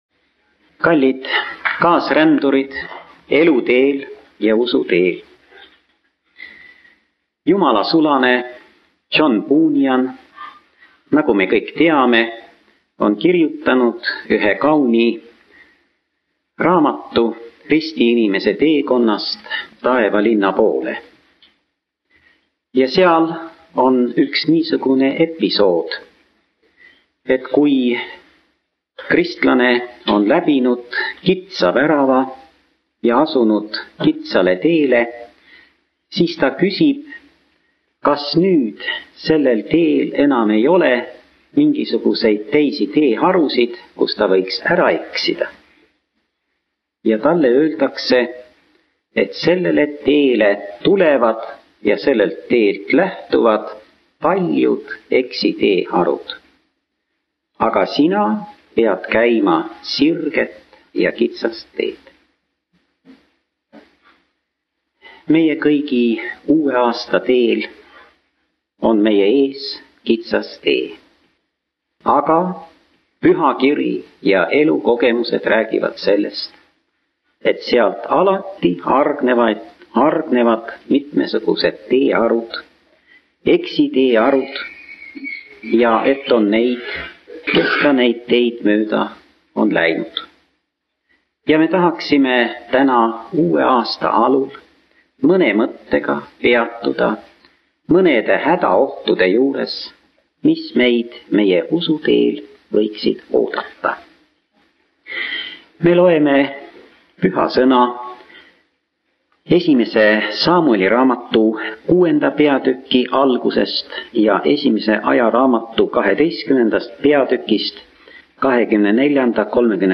Jutlus pärineb vaimulikust varasalvest ning on peetud 08. jaanuaril 1979. Sisu tutvustus: Jumala kogudust ja maailma eraldab selge piir.